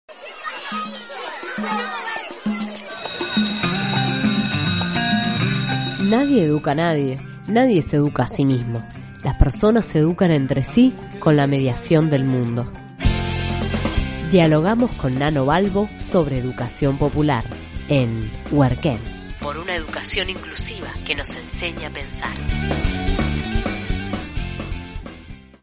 Locución
Separador columna en programa matutino